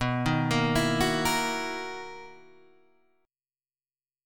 BM11 chord {7 6 8 9 7 6} chord